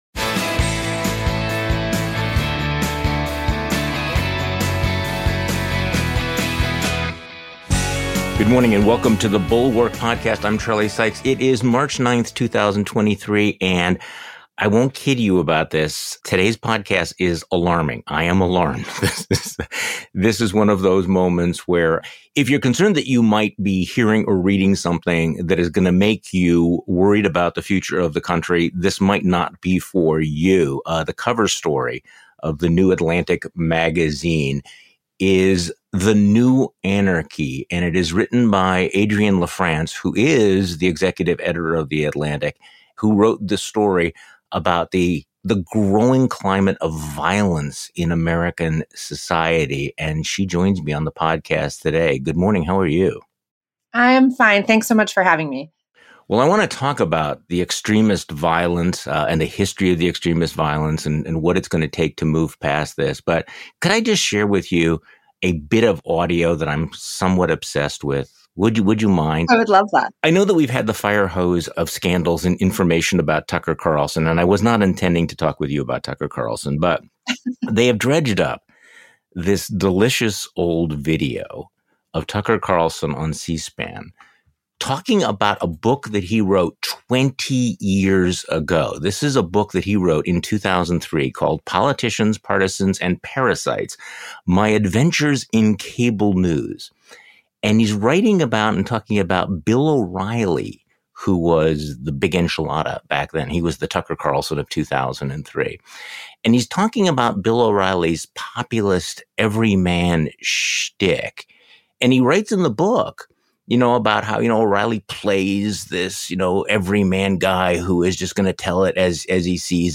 History can teach us about how America can survive this new phase of domestic terror. The Atlantic's Adrienne LaFrance joins Charlie Sykes today.